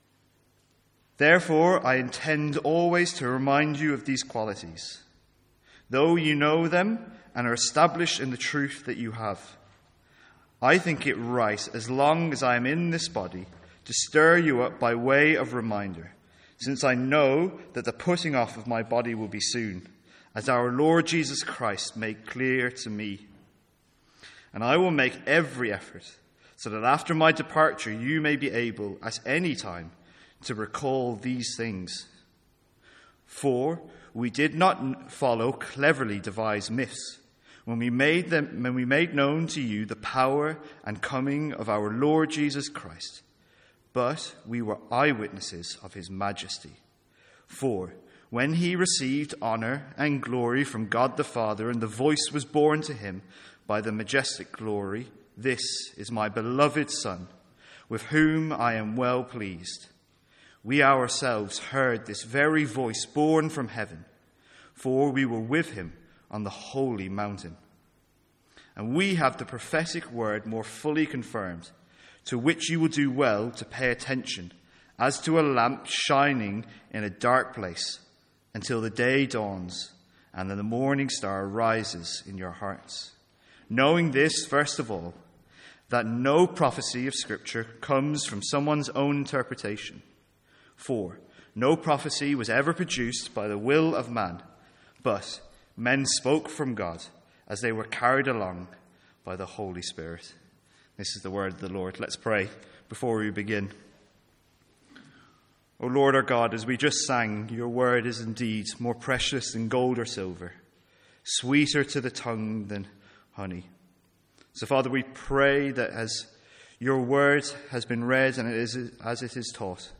Sermons | St Andrews Free Church
From our evening series in 2 Peter.